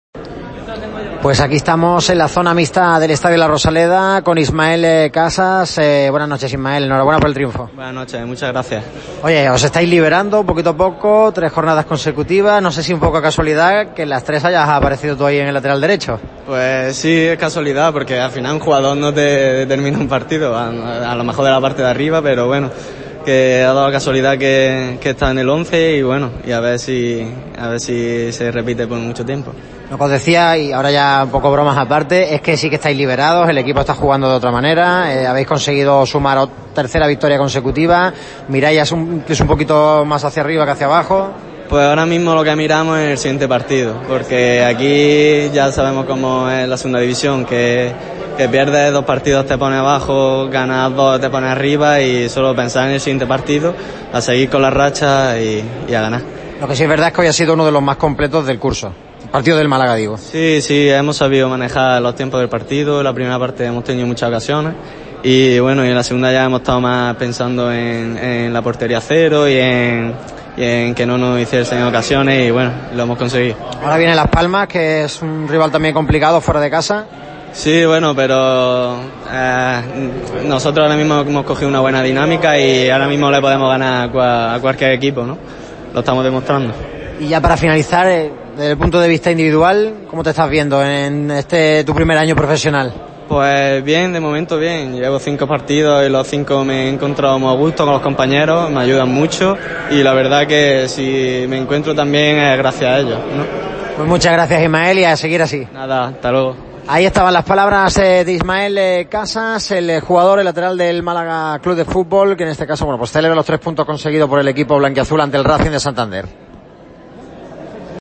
«Sí lo es porque un jugador no determina un partido, salvo en los equipos grandes. A ver si se repite por mucho tiempo», ha comentado en los micrófonos de Radio Marca Málaga.
ismael-casas-en-radio-marca-málaga.mp3